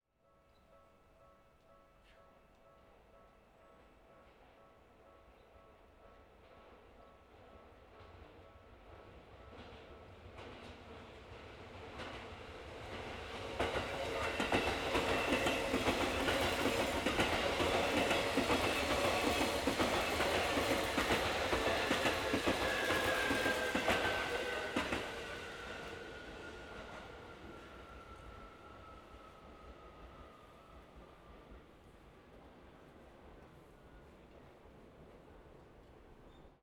続いて同じ96kHzの設定のまま今度は線路沿い、踏切近くに移動して電車が通り過ぎるところを録音してみた。
それぞれで電車のスピードが違うので、雰囲気は違っているが、明らかに120°のほうが、広い音場空間になっていることに気が付くだろう。
H2essential_train_120.wav